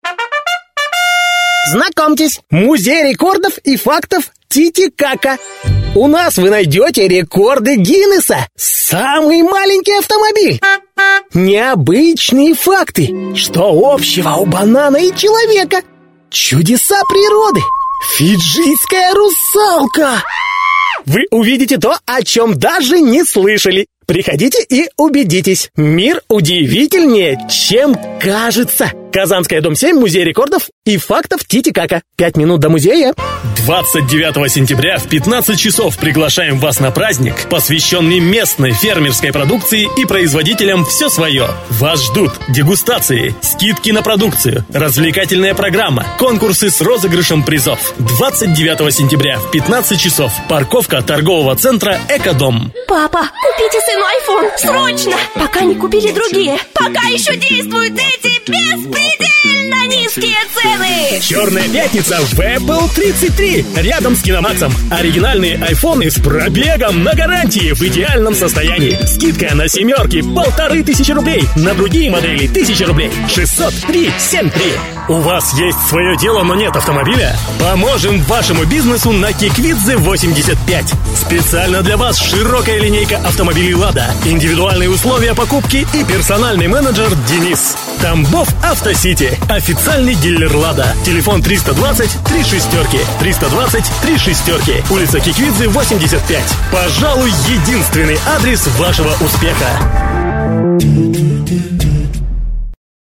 Молодой энергичный голос.
Тракт: микрофон RODE NTK, предусилитель dbx-376, карта TC Impact Twin(FireWire), Акустическая кабина(Mappysil пирамидки)